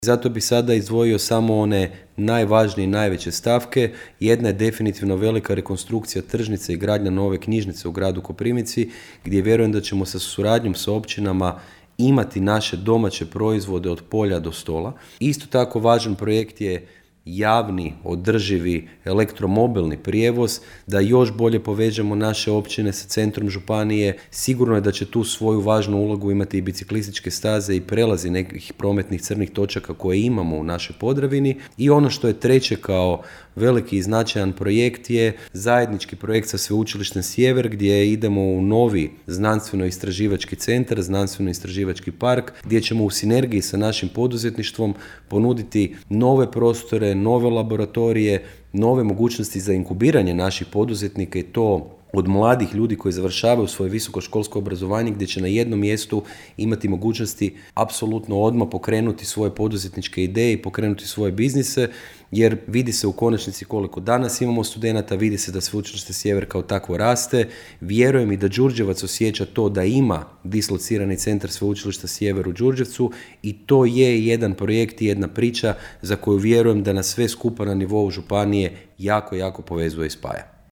Što je točno ITU mehanizam i što on znači za Koprivnicu po pitanju projekata, pojasnio je za Podravski radio upravo gradonačelnik Grada Koprivnice i saborski zastupnik, Mišel Jakšić u emisiji Koprivničke teme.